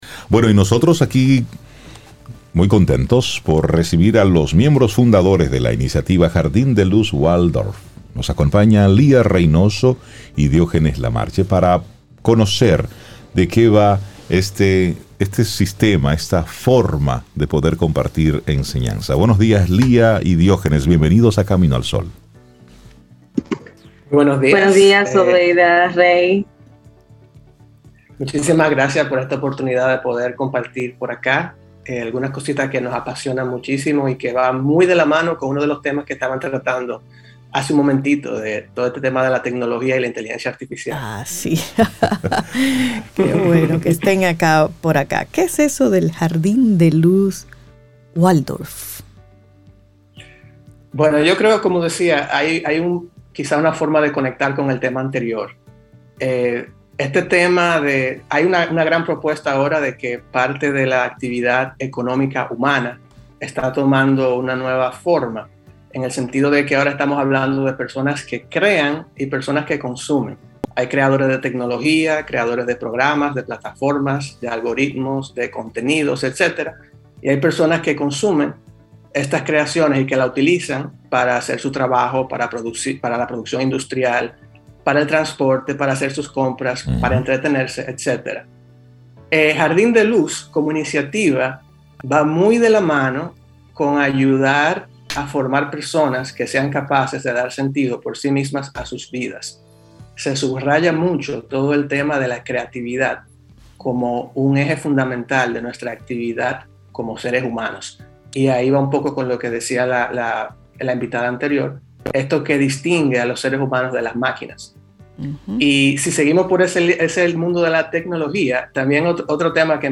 Entrevista | Conociendo el Jardín de Luz Waldorf - Camino Al Sol